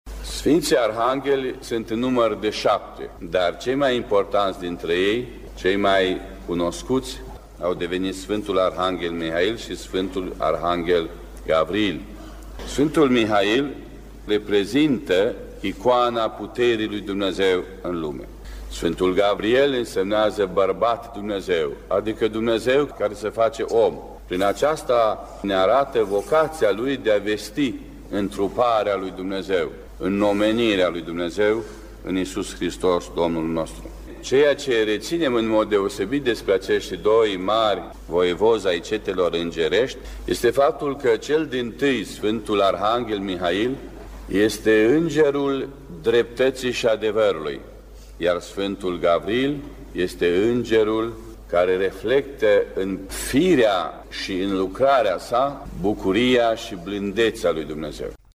Patriarhul Bisericii Ortodoxe Române, Prea Fericitul Daniel, despre semnificația sărbătorii de astăzi: